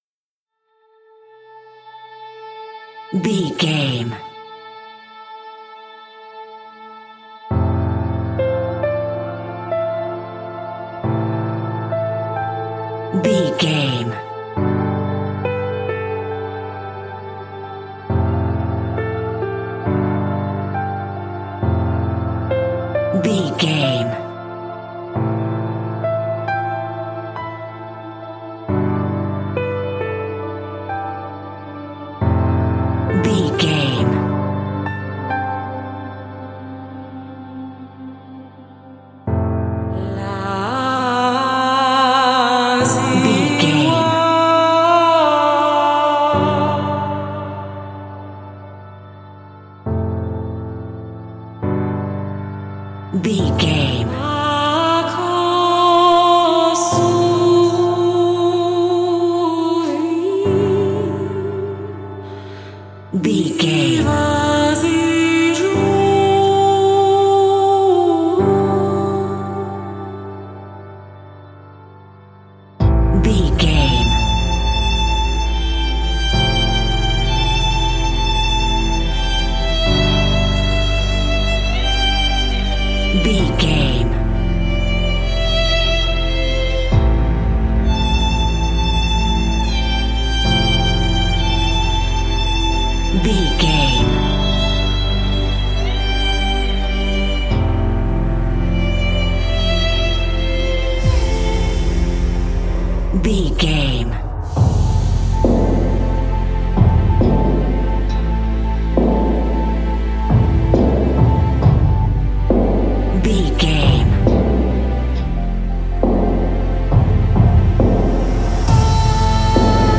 Aeolian/Minor
epic music
heroic music
trailer music
orchestra
vocals
percussion
piano
strings